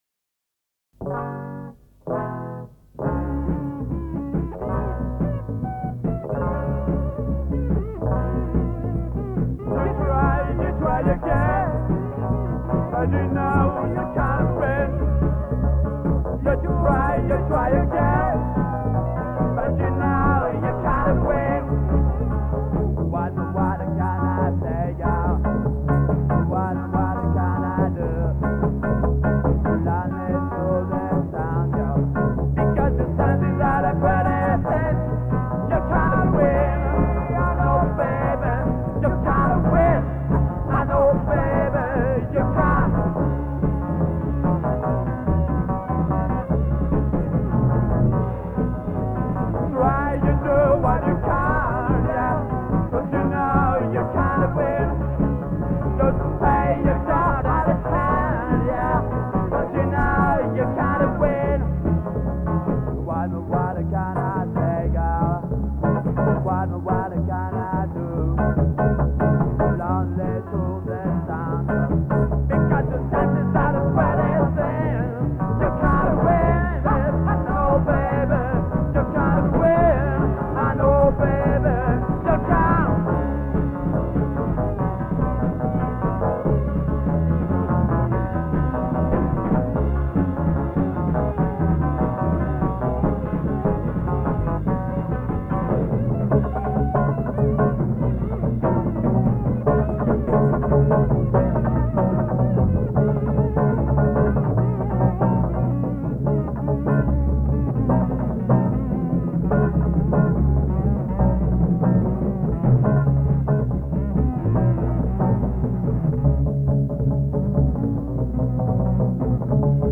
Rock LYON Sixties 1965-1969